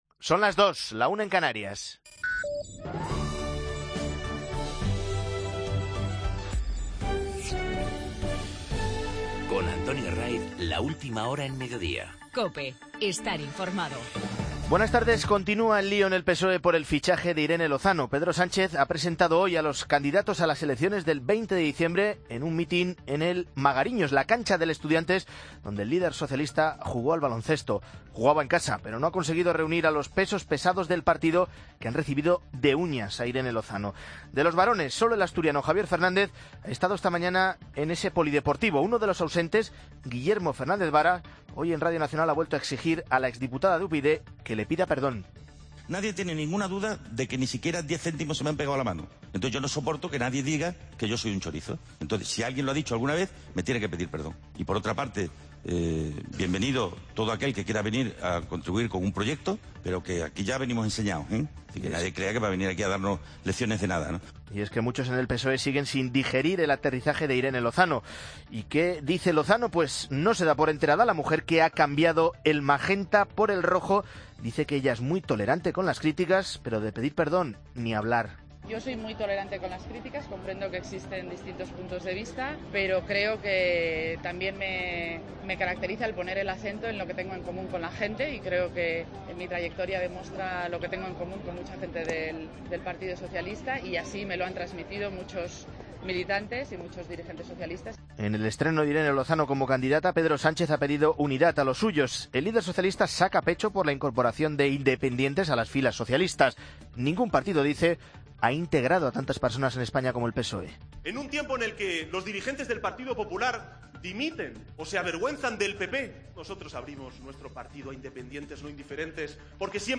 el equipo de informativos de fin de semana.